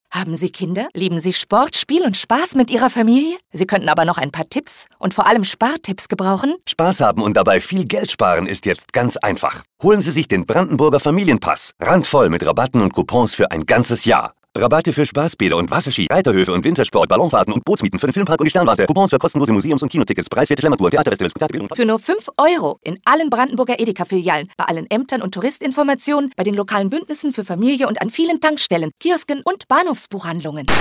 Familienpass | Kino | frisch | 0.30